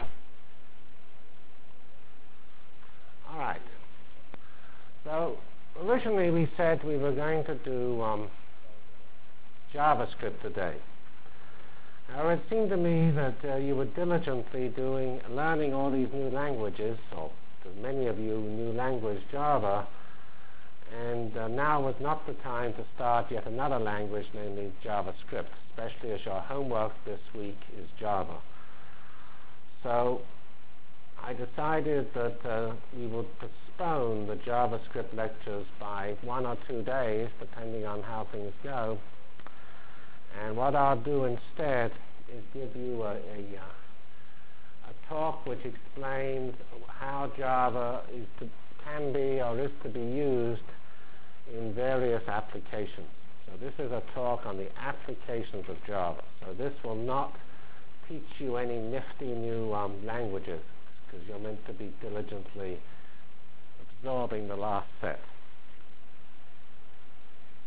Foil 1 CPS 616 Java Lectures